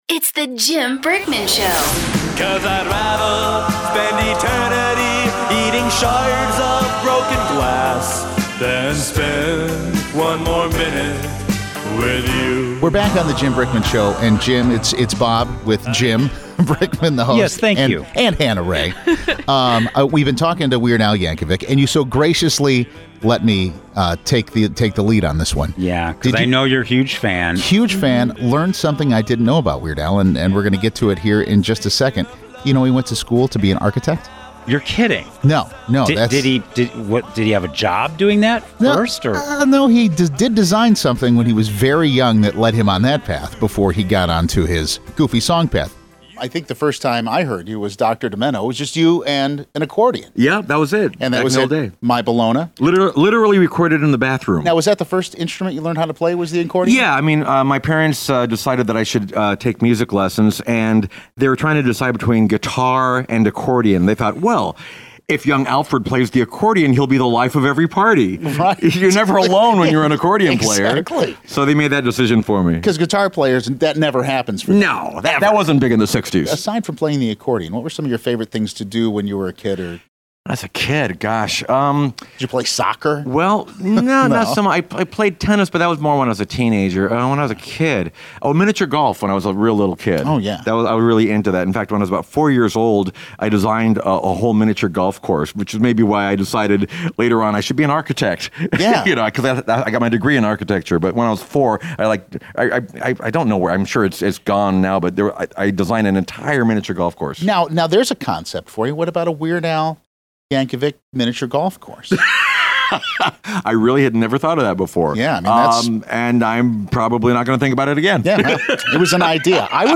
part two of our interview with Weird Al here!